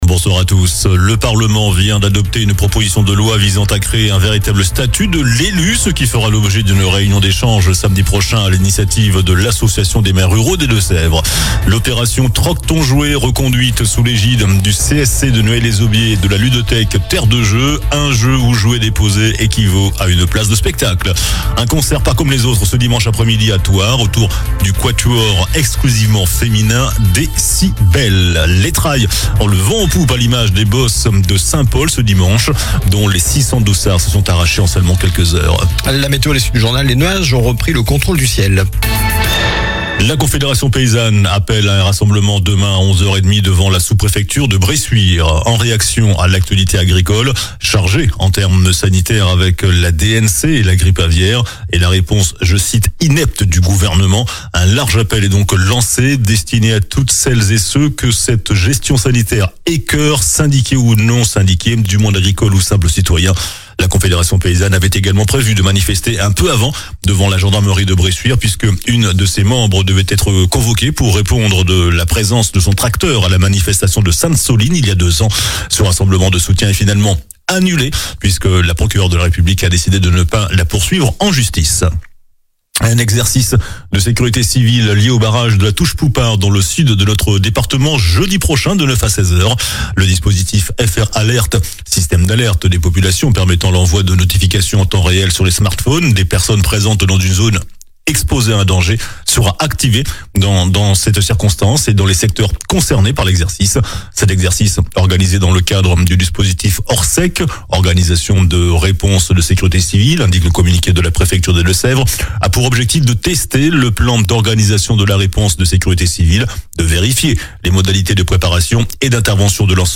JOURNAL DU MARDI 09 DECEMBRE ( SOIR )